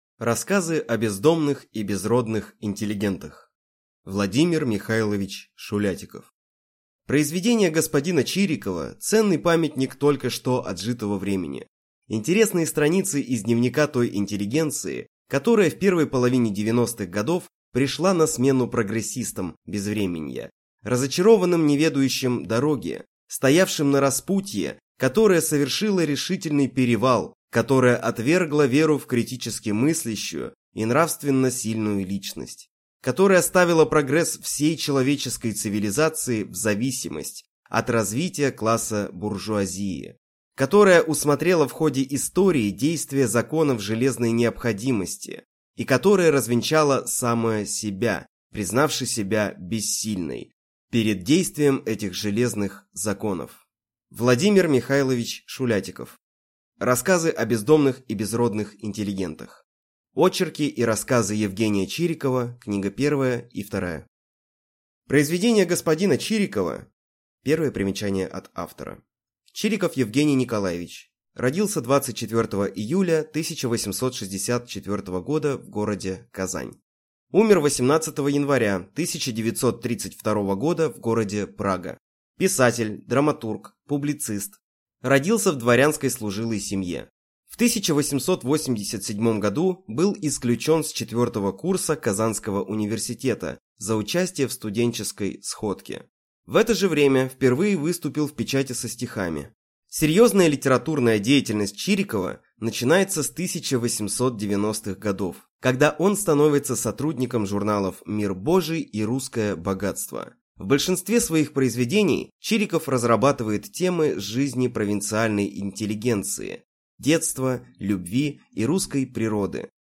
Аудиокнига Рассказы о «бездомных и безродных» интеллигентах | Библиотека аудиокниг